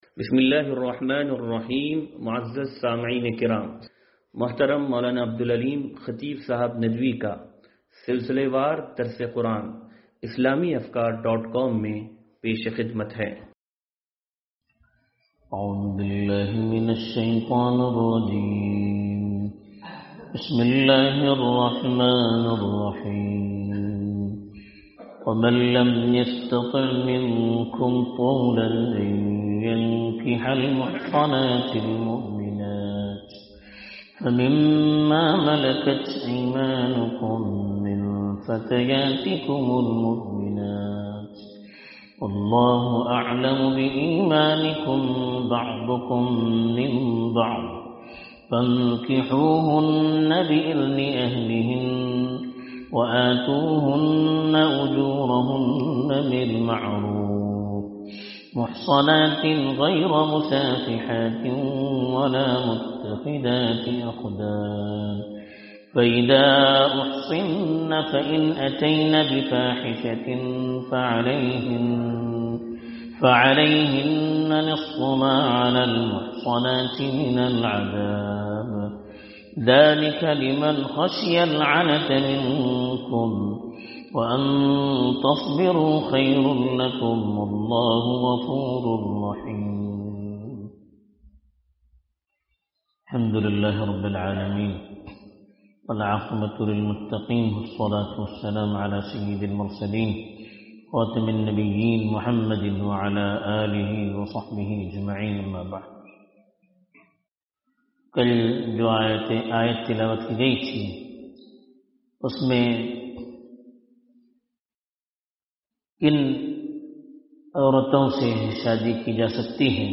درس قرآن نمبر 0339